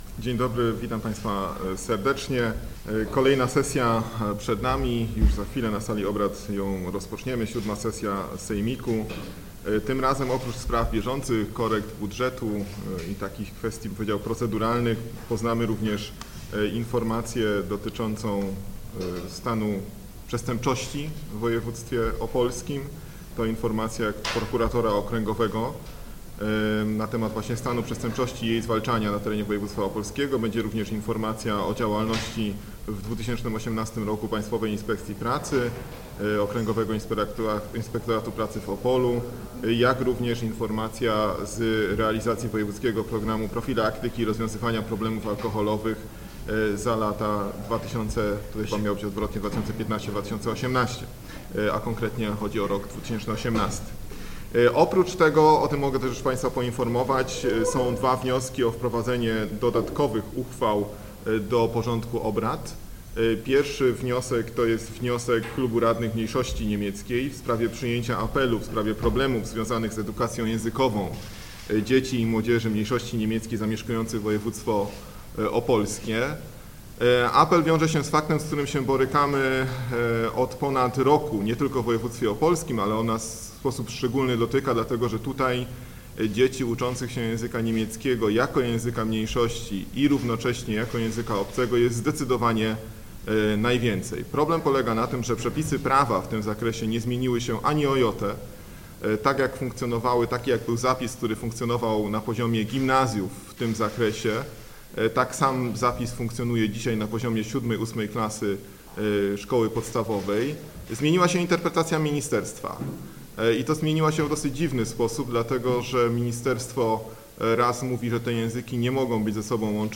konferencja-prasowa-sesja.mp3